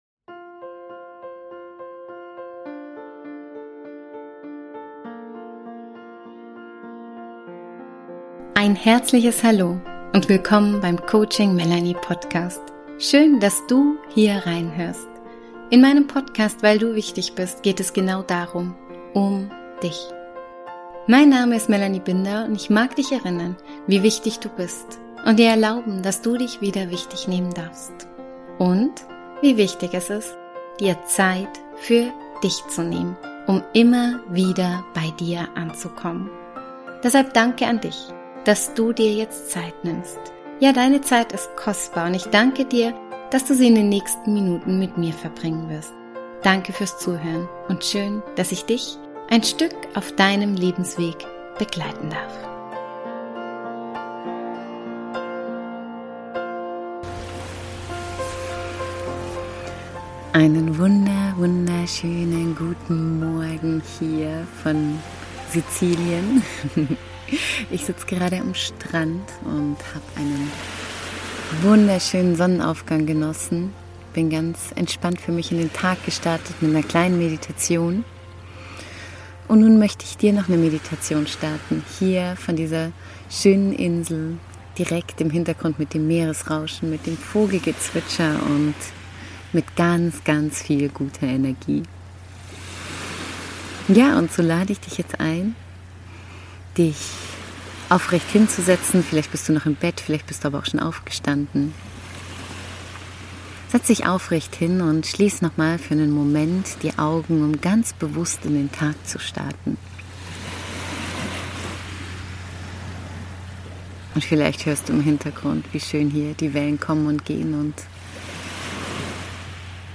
Starte deinen Tag mit einer sanften Morgen-Meditation, die ich für dich an einem ganz besonderen Ort aufgenommen habe: in Sizilien, direkt am Meer, mit der aufgehenden Sonne im Gesicht, barfuß im Sand und tief verbunden mi...
Mitten im Klang der Natur und im Einklang mit dir .